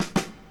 Quick Fill.wav